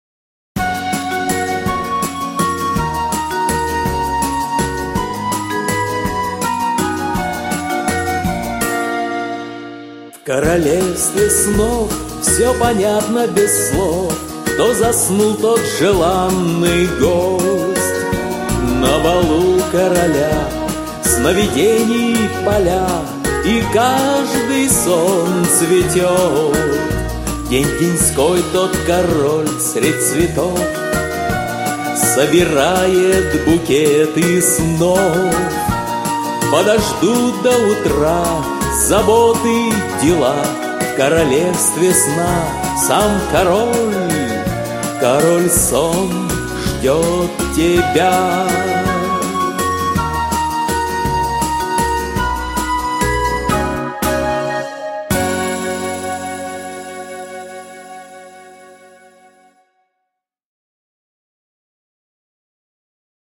Музыкальная вариация на тему колыбельной